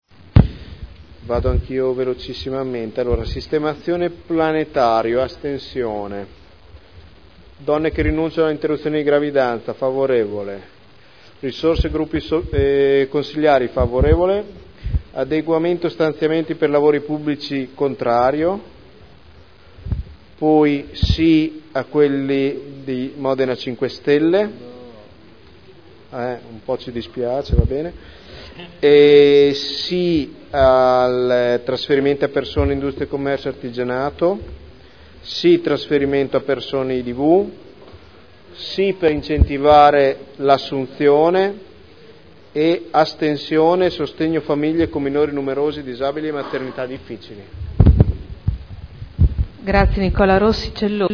Nicola Rossi — Sito Audio Consiglio Comunale
Seduta del 28/03/2011. Dichiarazioni di voto su emendamenti.